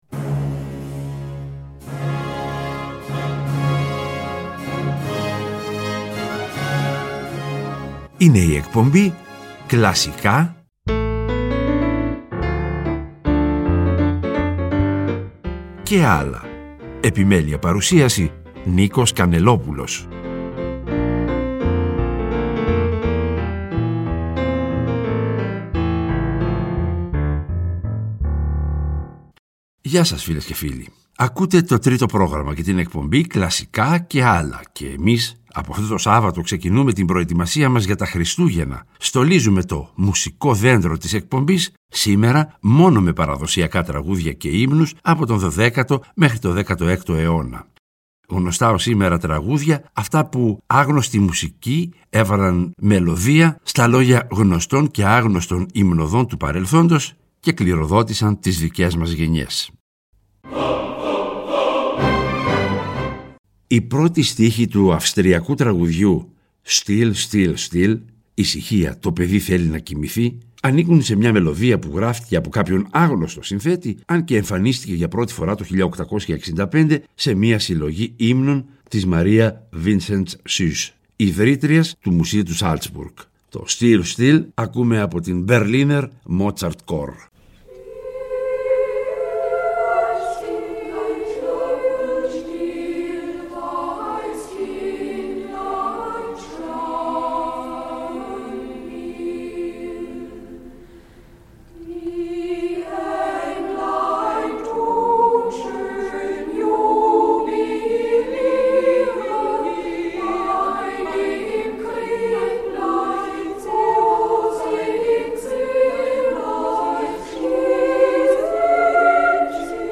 Από αυτό το Σάββατο ξεκινούμε την προετοιμασία μας για τα Χριστούγεννα. Στολίζουμε το «μουσικό δέντρο» της εκπομπής μόνο με παραδοσιακά τραγούδια και ύμνους, από τον 12ο έως τον 16ο αιώνα.